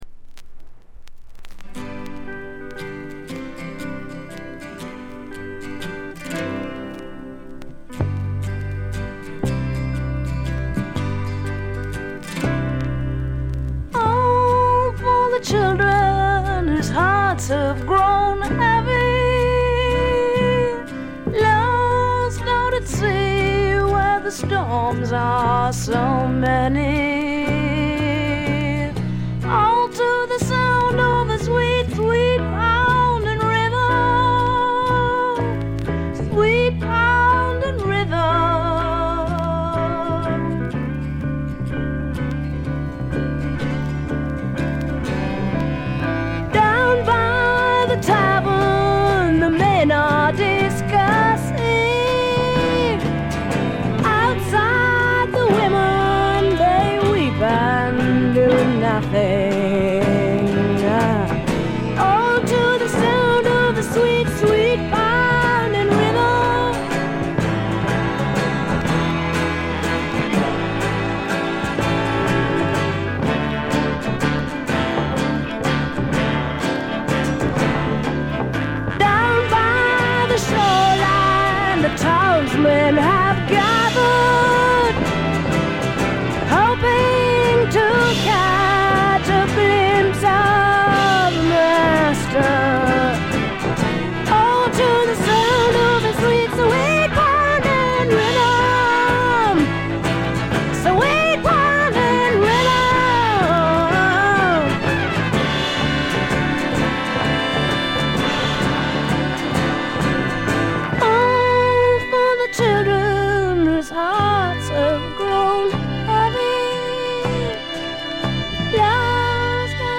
カナダ出身の女性シンガーソングライターが残したサイケ／アシッド・フォークの大傑作です。
試聴曲は現品からの取り込み音源です。